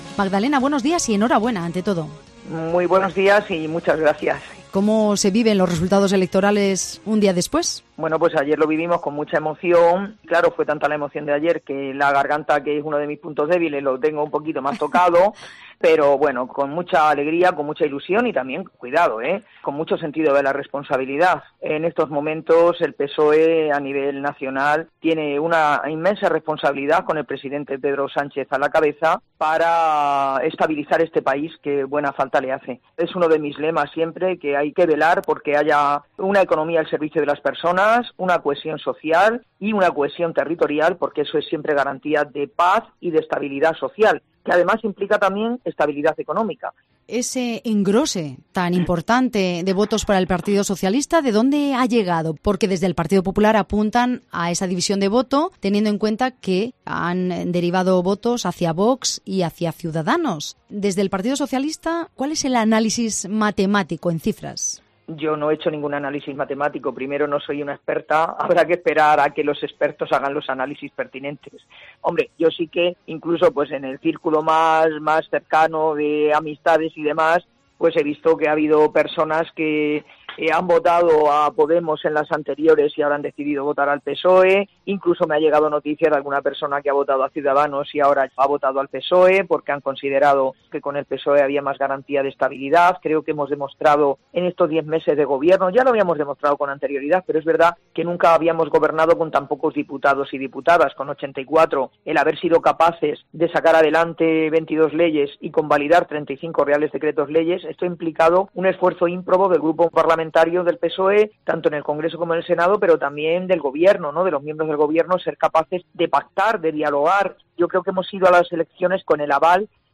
La ministra de Trabajo, Migraciones y Seguridad SocialMagdalena Valerio, realiza, en los micrófonos de Cope Guadalajara, balance de los resultados de las Elecciones Generales celebradas ayer domingo 28 de abril, según los cuales Valerio ocupará el escaño socialista por la provincia de Guadalajara en el Congreso de los Diputados.